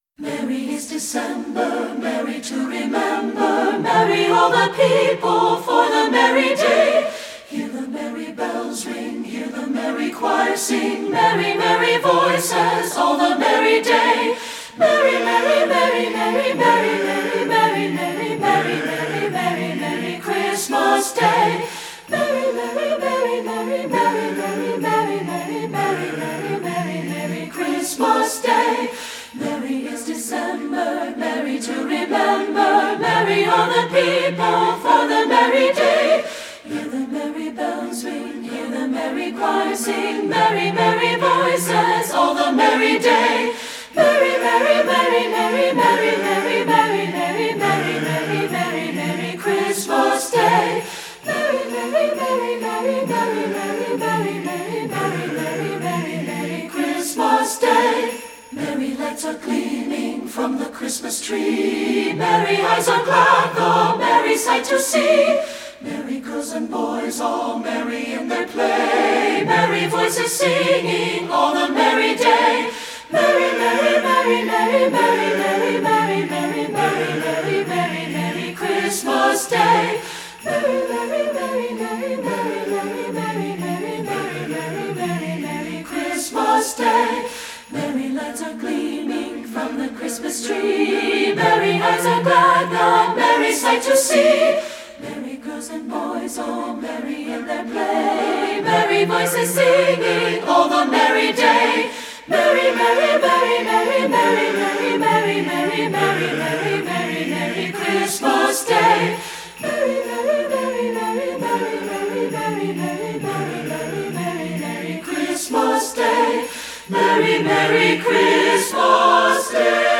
Voicing: 3-Part Mixed a cappella